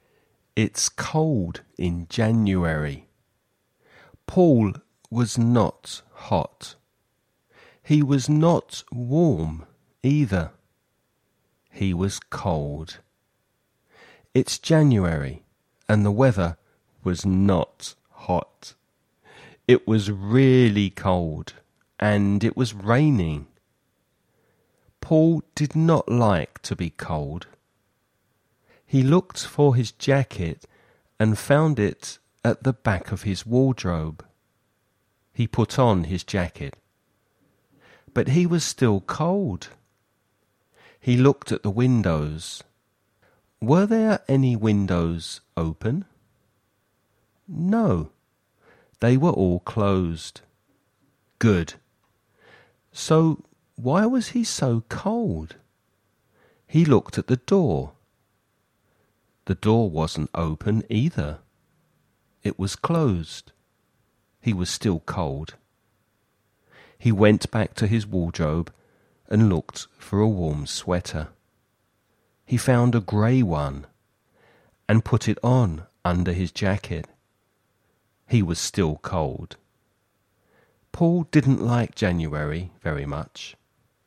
Listening Practice
You’re going to listen to a man talking about artificial rain.